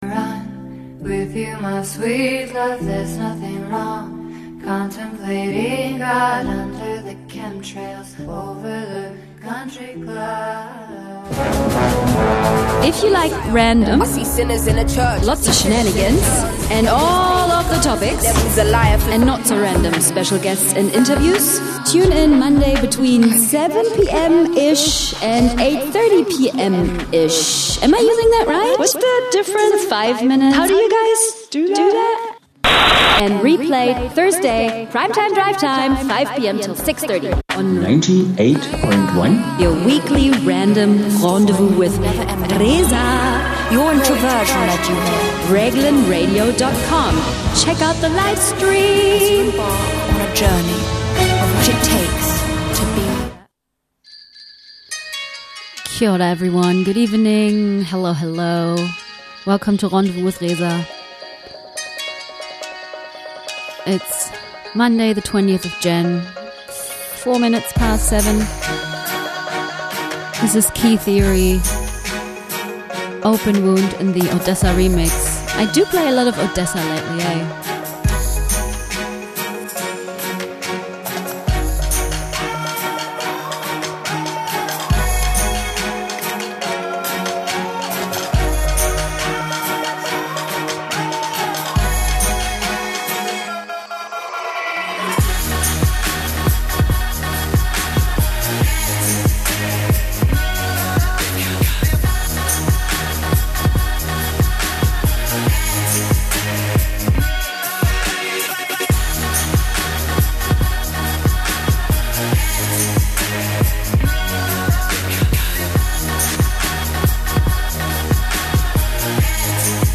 what can i say, its just always the same: lots of randomness, some rage and a bit of sillyness. electro rules the show (mostly)